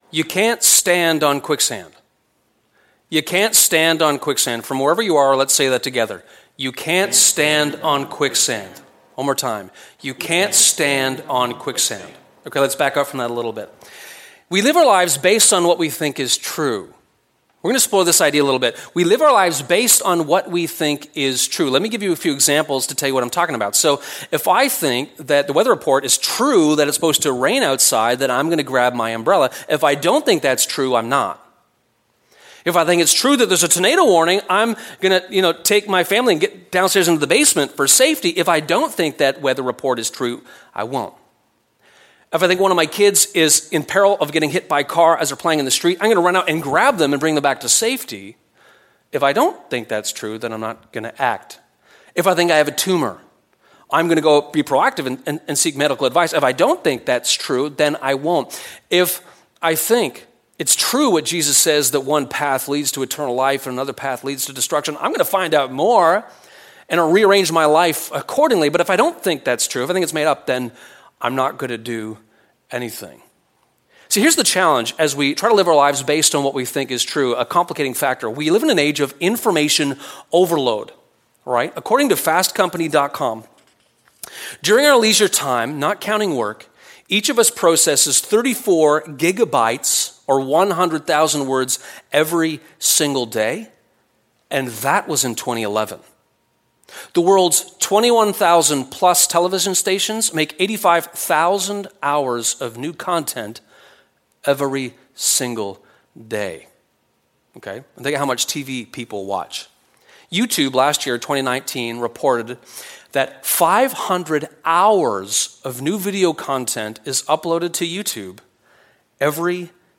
You can’t stand on quicksand [Sermon]